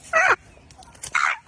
鸵鸟叫声 非洲鸵鸟鸣叫